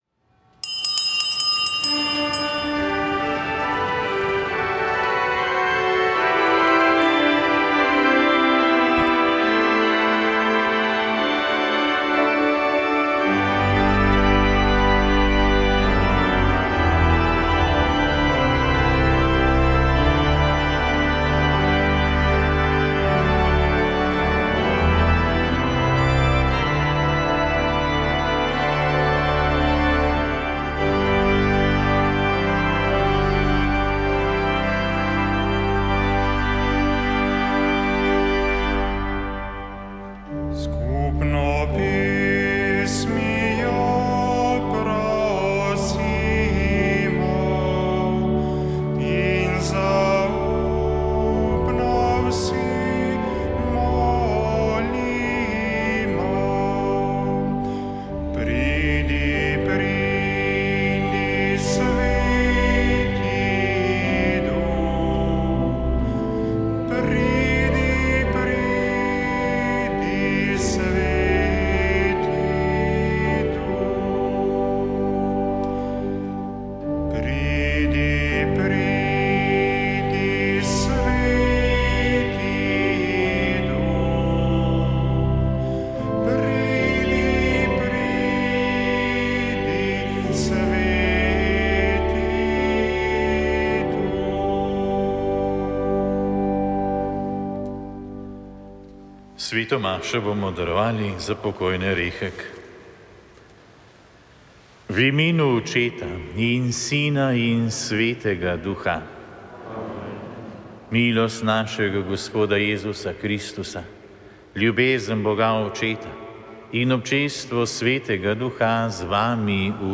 Sveta maša
Prenos maše iz bazilike Marije Pomagaj z Brezij dne 1. 5.